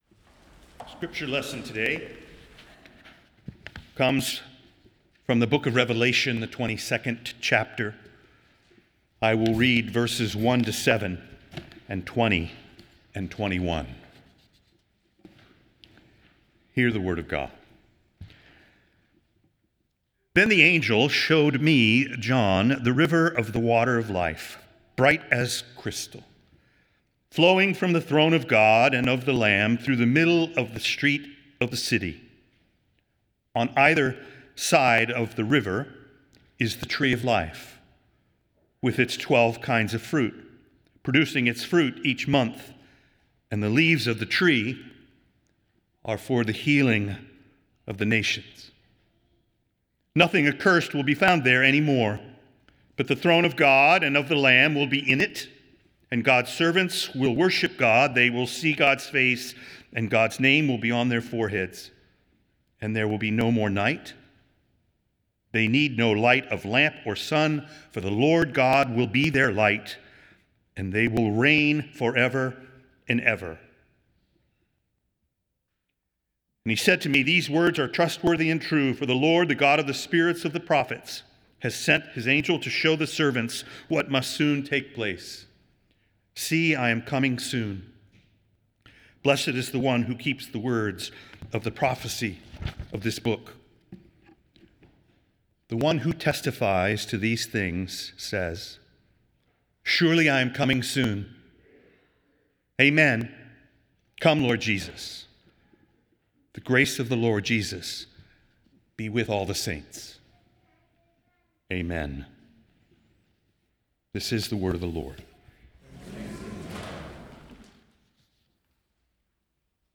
Nassau Presbyterian Church Sermon Journal Quickly Nov 30 2025 | 00:13:18 1x 00:00 / 00:13:18 Subscribe Share Apple Podcasts Spotify Amazon Music Overcast RSS Feed Share Link Embed